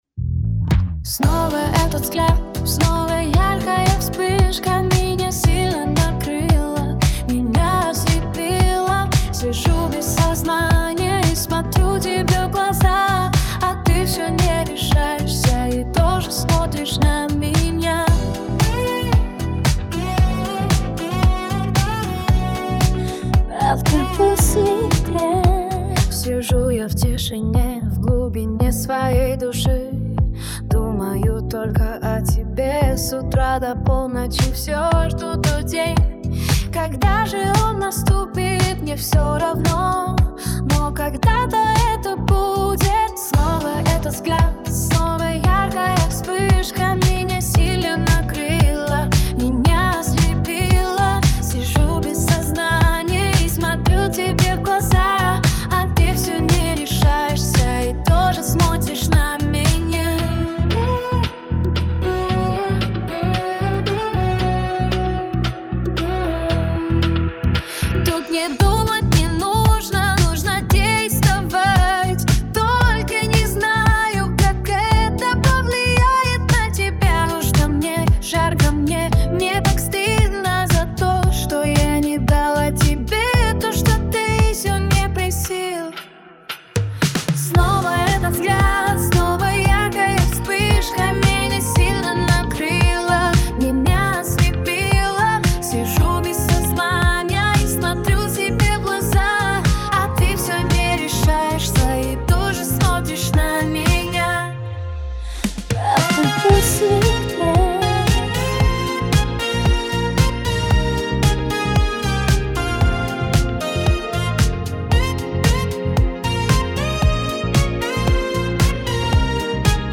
Пример музыки и голоса создан с помощью AI.